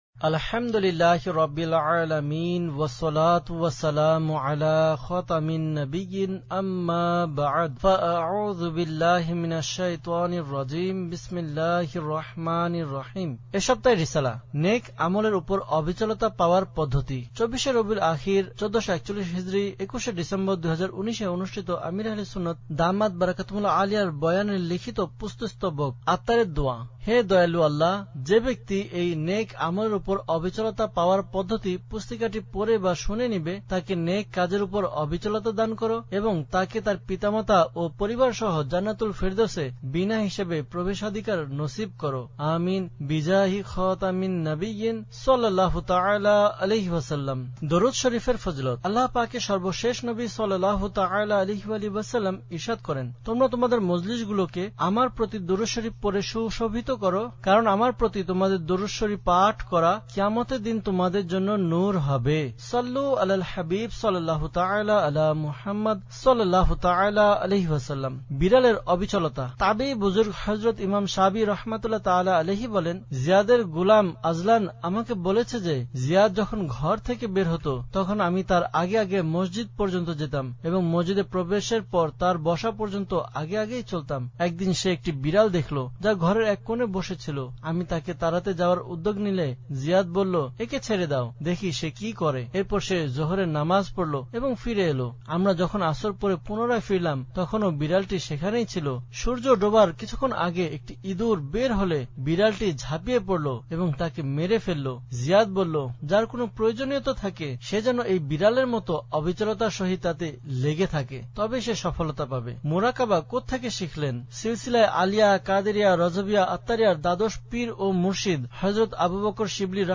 Audiobook - নেক আমলের উপর অবিচলতা পাওয়ার পদ্ধতি (Bangla)